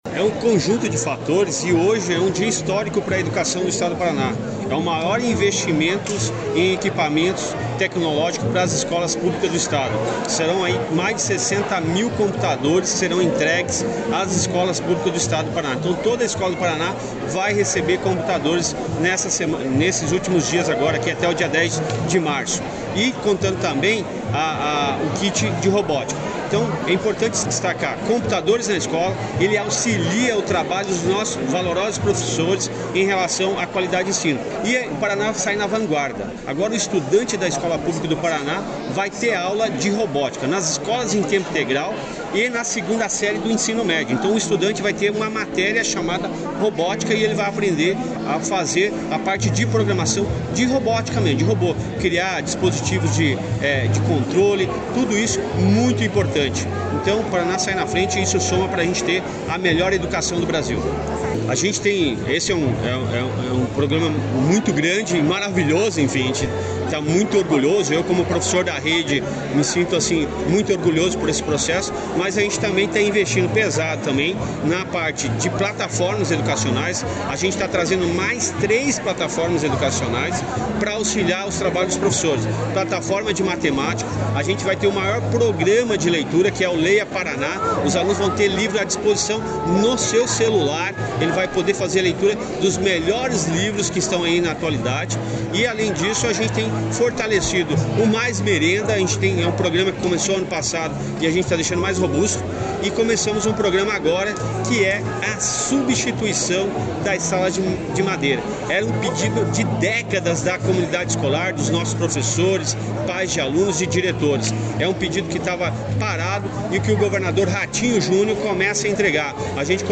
Sonora do secretário da Educação, Roni Miranda, sobre a entrega de 77 mil novos equipamentos de informática para a rede de ensino do Paraná